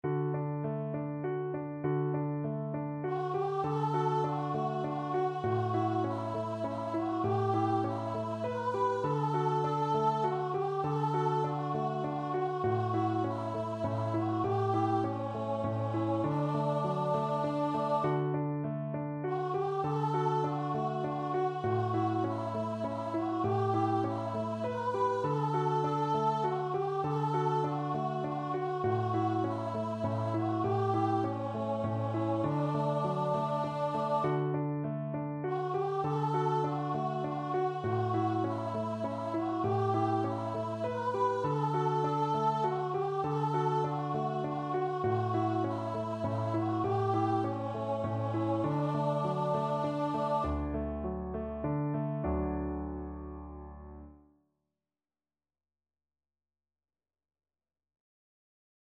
Voice
D major (Sounding Pitch) (View more D major Music for Voice )
Gently flowing
Swiss